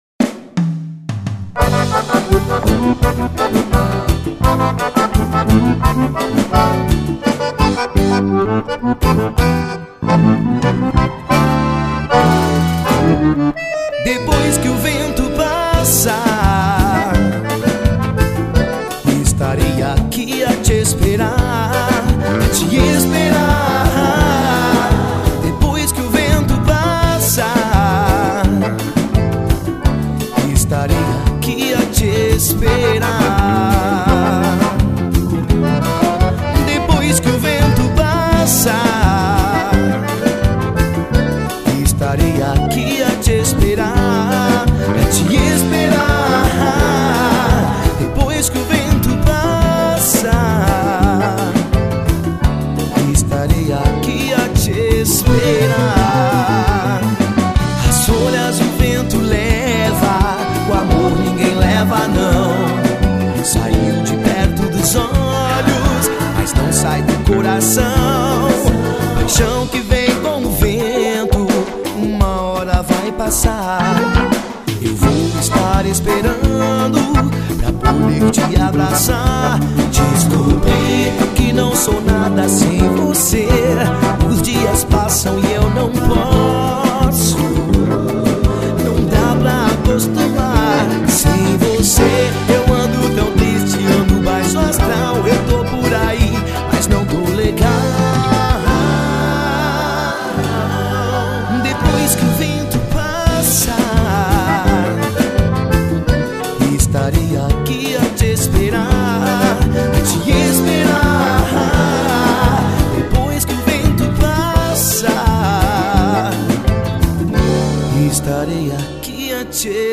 Batidão Gaúcho.